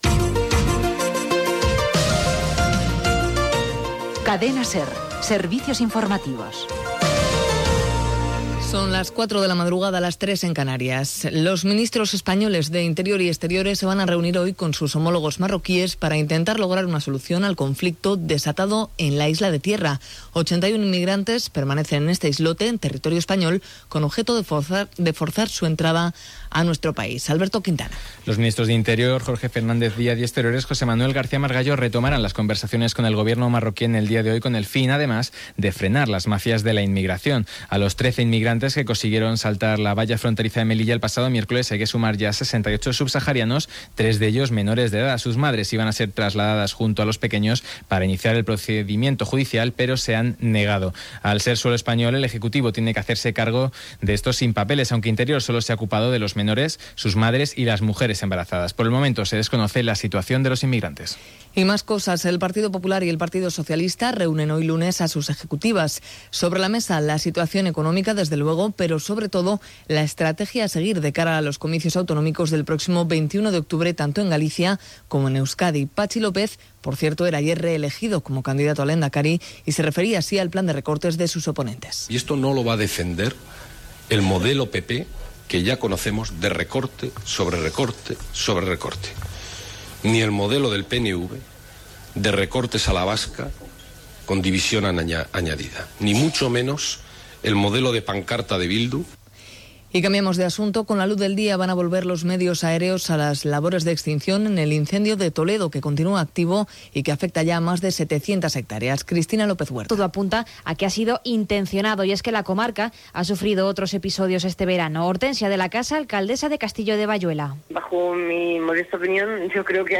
Careta del programa, hora, reunió de ministres espanyols i marroquins pel conflicte de l'illa de Piedra, eleccions basques, incendi a Toledo, resultats de la lliga de futbol masculí i Vuelta a España, el temps, careta de sortida.
Informatiu
Últim butlletí de notícies amb aquesta sintonia d'entrada que es va fer servir des de l'any 1991.